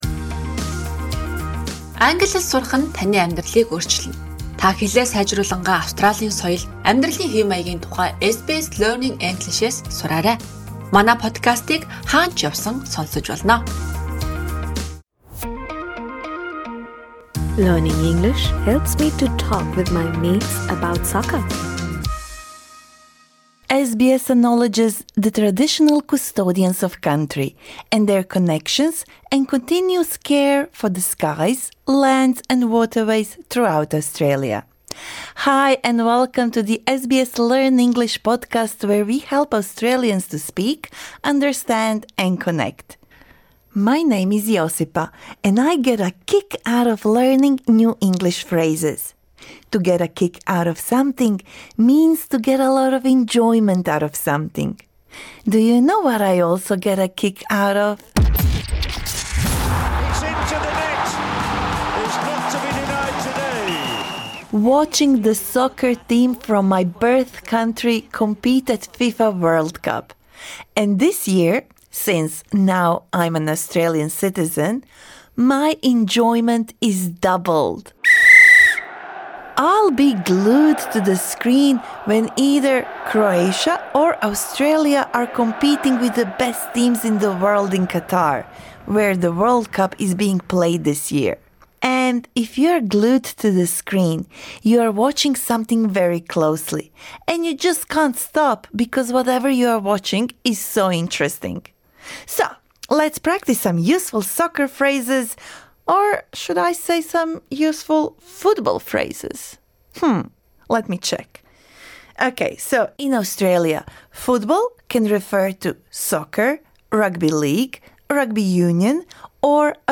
SBS Learn English will help you speak, understand and connect in Australia - view all episodes This lesson suits upper-intermediate to advanced learners.